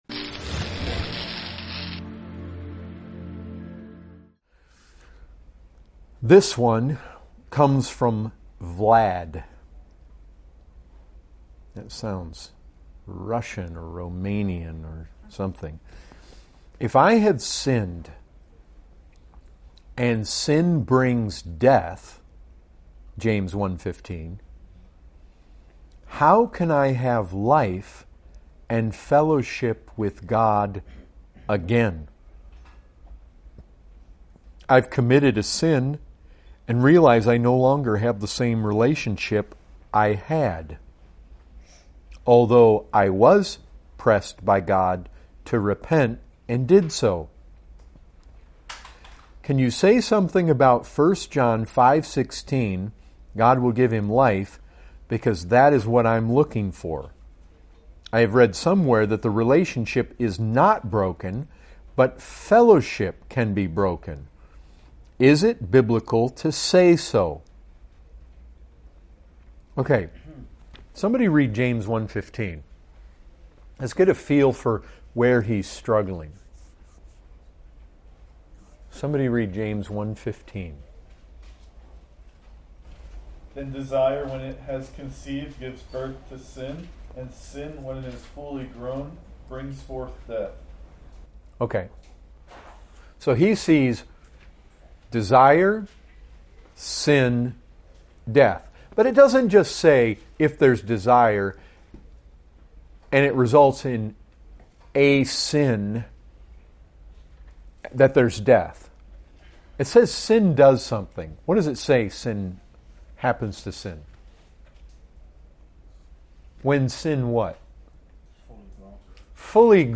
2017 Category: Questions & Answers Topic